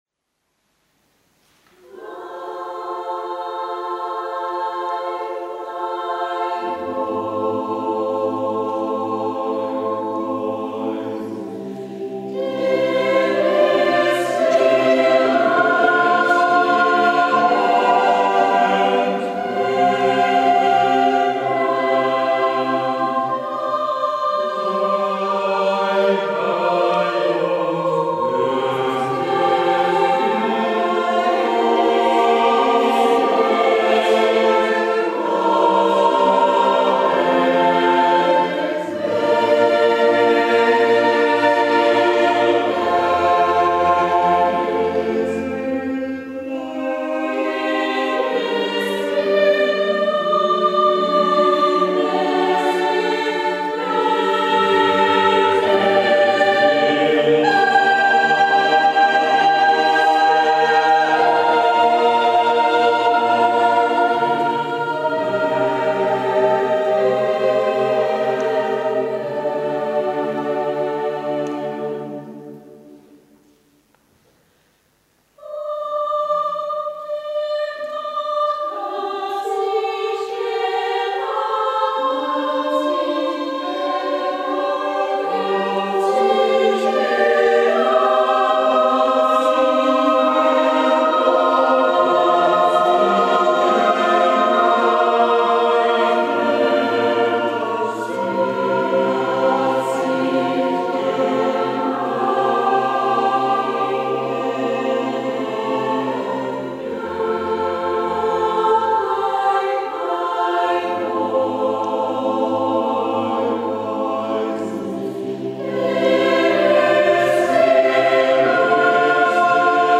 Det är melodiskt och klingande men utnyttjar det instrument som kören är till fullo.